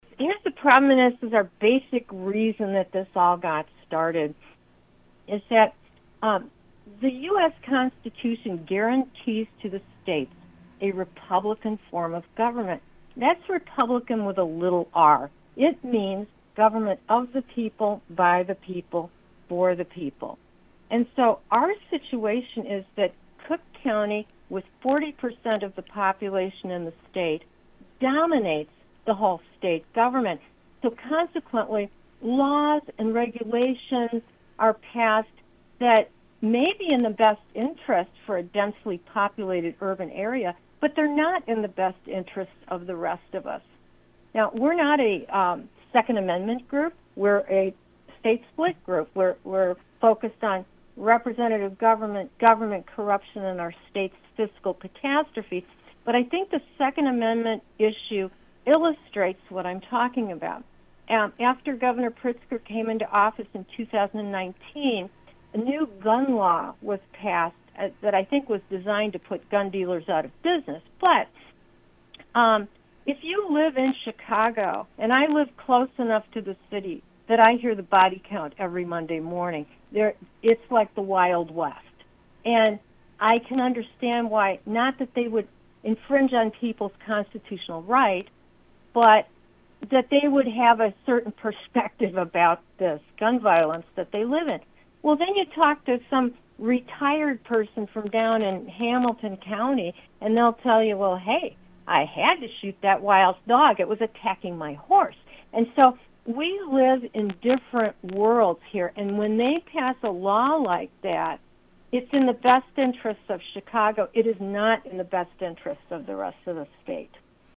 new-il-interview-part-3.mp3